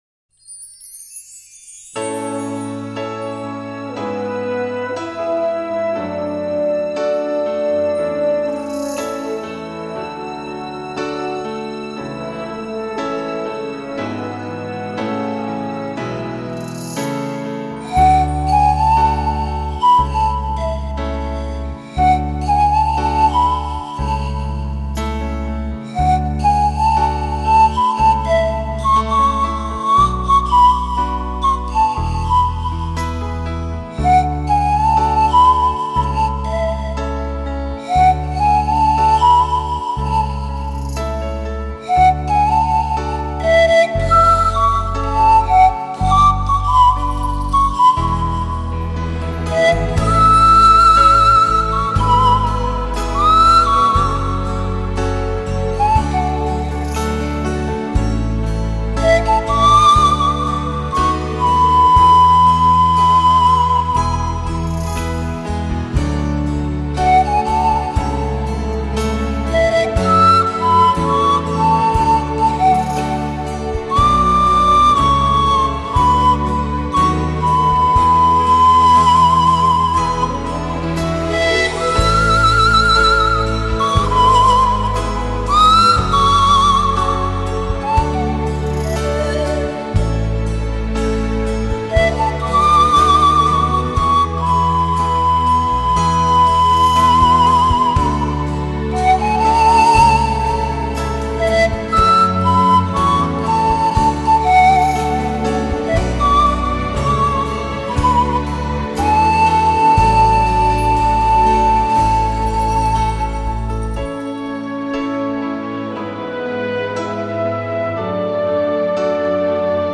флейта